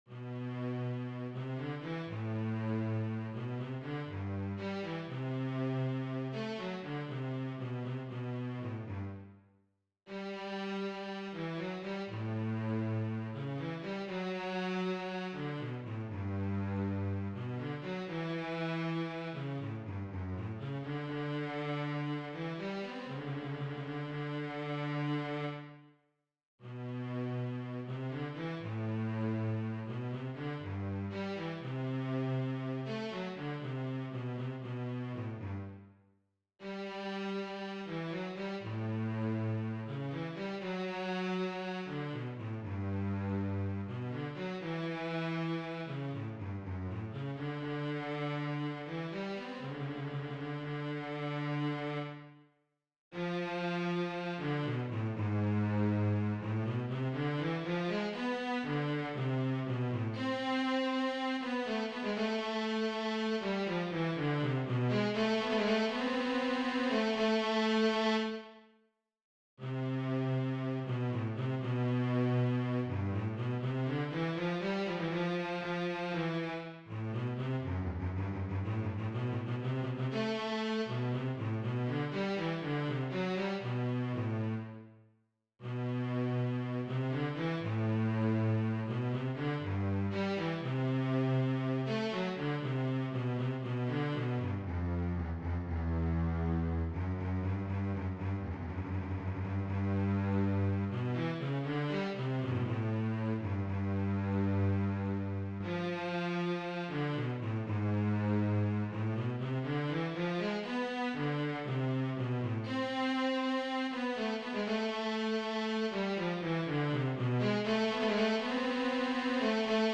DIGITAL SHEET MUSIC - CELLO SOLO